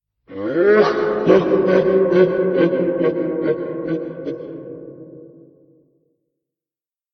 snd_evillaugh.ogg